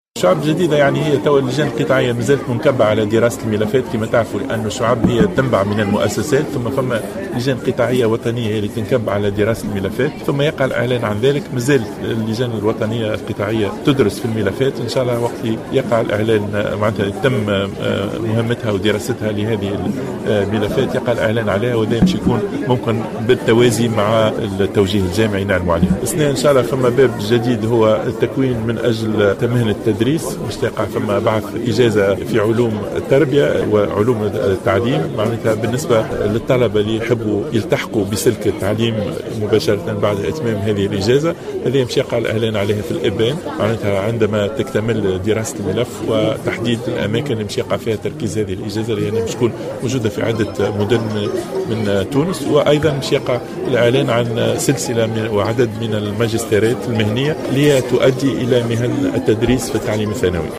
أعلن وزير التعليم العالي شهاب بودن خلال حضوره اليوم الأحد 24 أفريل 2016 تظاهرة طلابية بحي الفجاء بمدنين عن إحداث شعب جديدة سيتم إدراجها في كتاب التوجيه الجامعي لهذه السنة.
تصريح